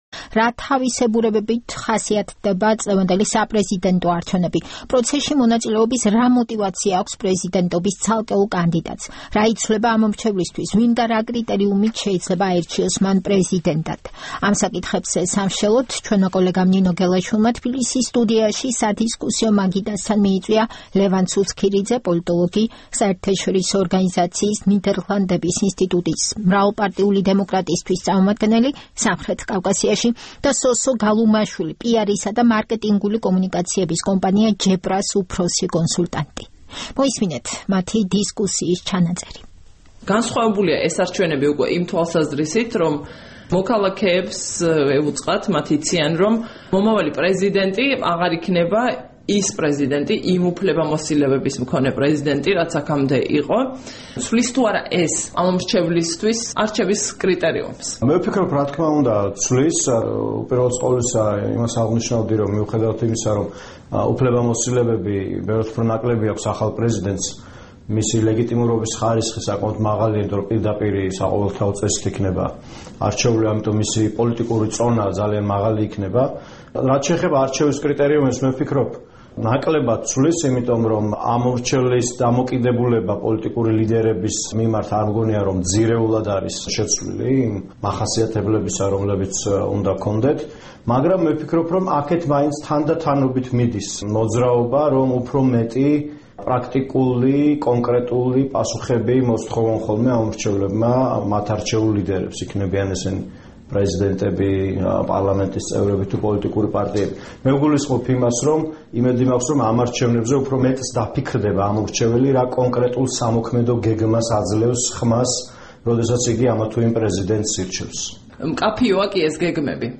ჩვენი დღევანდელი დისკუსია 27 ოქტომბრის არჩევნებს ეხება. რა თავისებურებებით ხასიათდება წლევანდელი საპრეზიდენტო არჩევნები? პროცესში მონაწილეობის რა მოტივაცია აქვს პრეზიდენტობის ცალკეულ კანდიდატს?